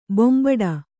♪ bombaḍa